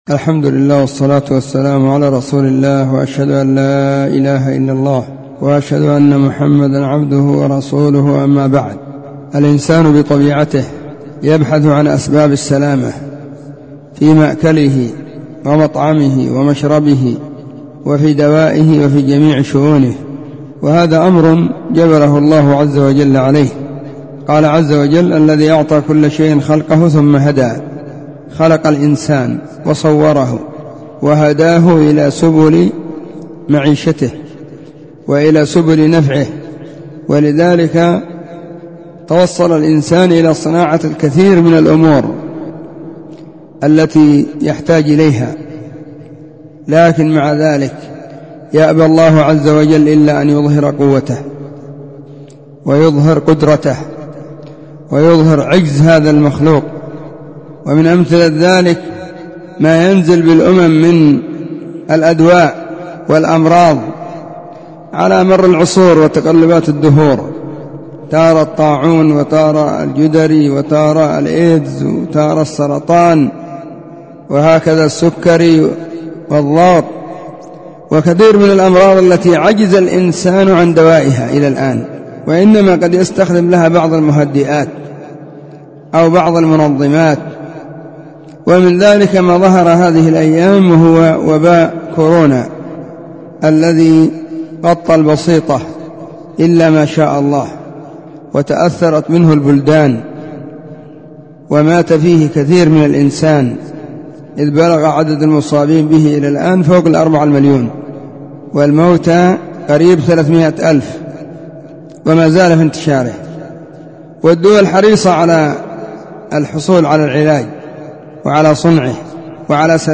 💢نصيحة قيمة بعنوان💢